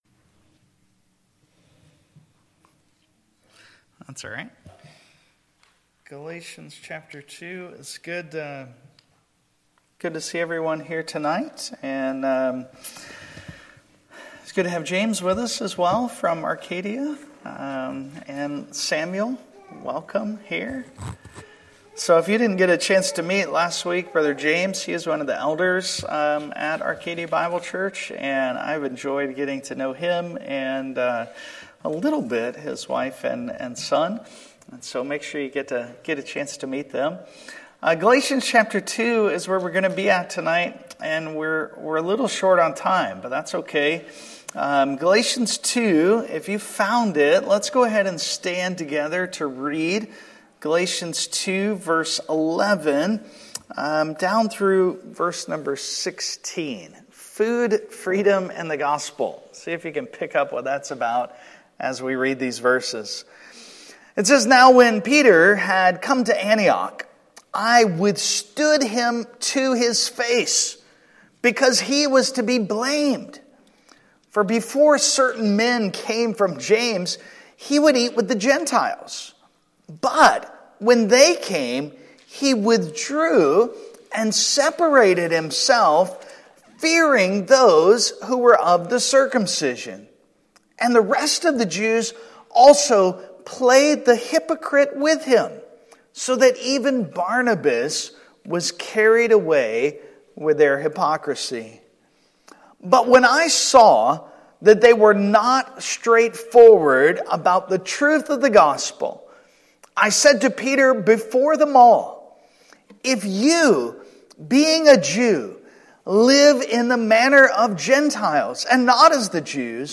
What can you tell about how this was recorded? Passage: Galatians 2:11-16 Service Type: Sunday Evening